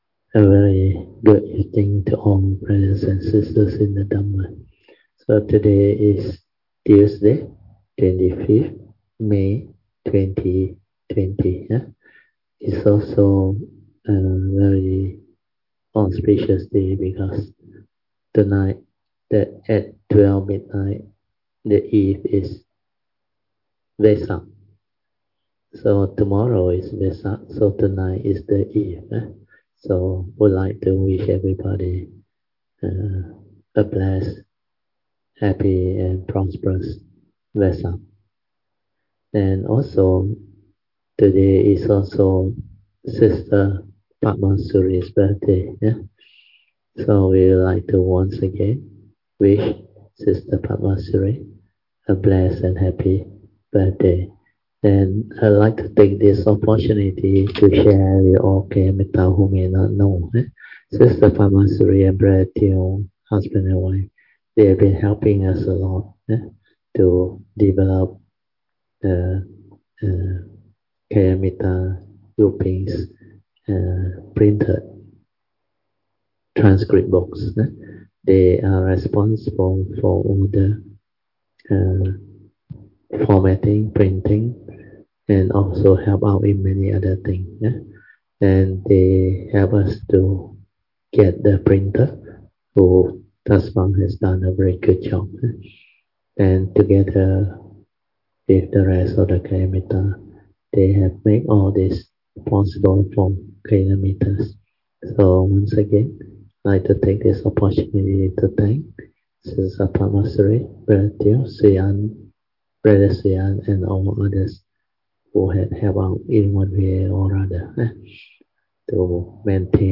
Tuesday Class